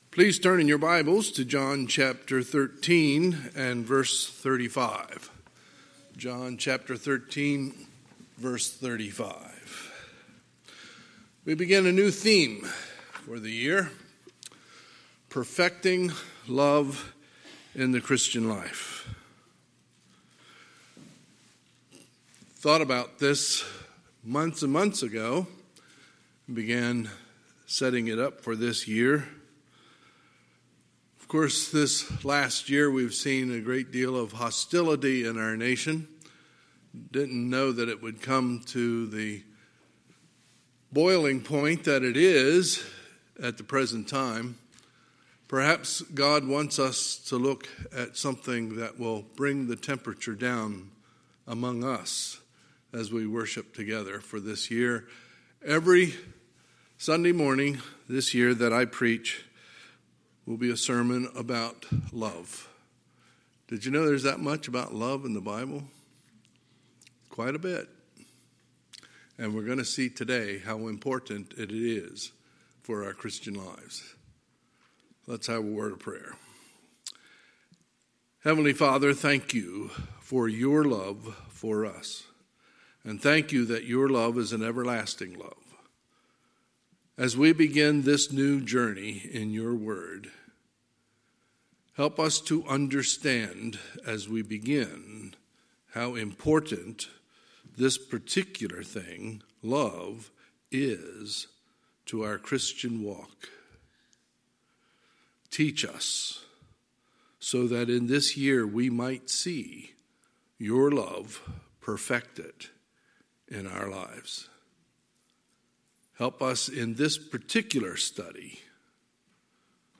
Sunday, January 17, 2021 – Sunday AM